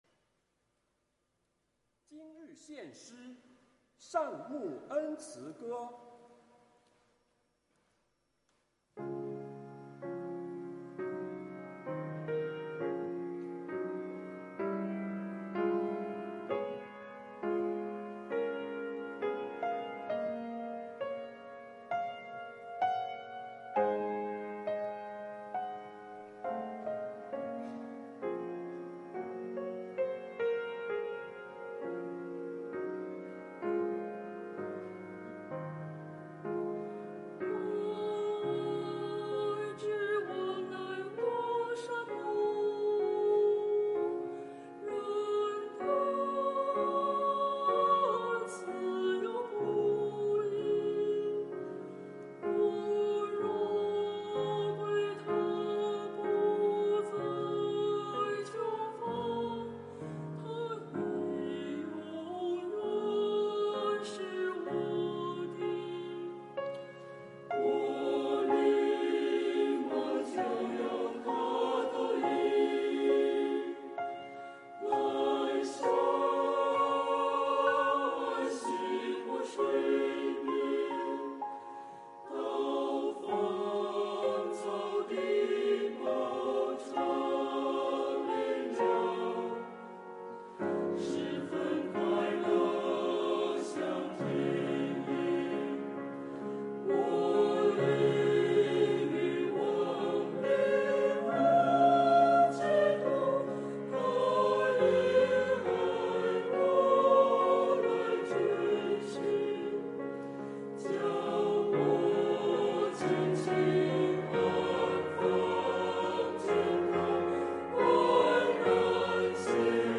[2023年4月30日主日献唱]《善牧恩慈歌》 | 北京基督教会海淀堂
团契名称: 大诗班 新闻分类: 诗班献诗 音频: 下载证道音频 (如果无法下载请右键点击链接选择"另存为") 视频: 下载此视频 (如果无法下载请右键点击链接选择"另存为")